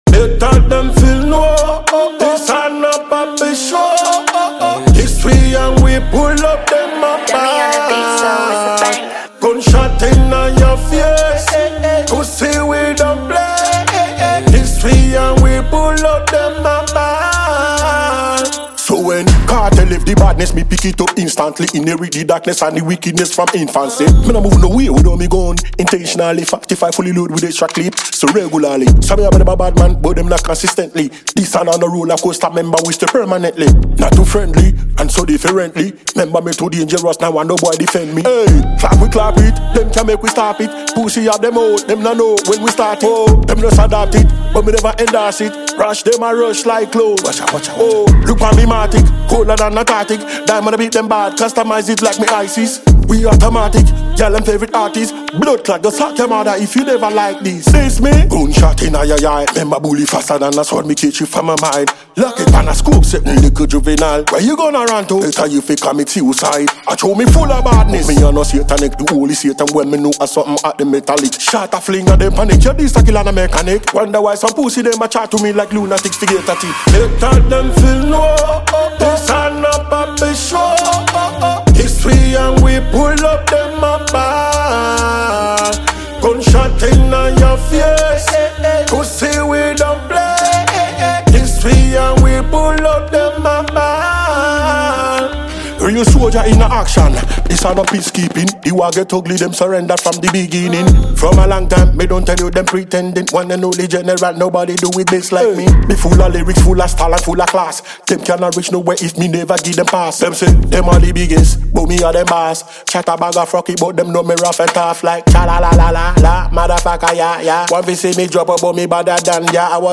is an energetic dancehall release
• Genre: Dancehall / Reggae